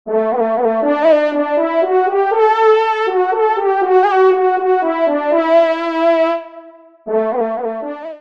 FANFARE
Pupitre de Chant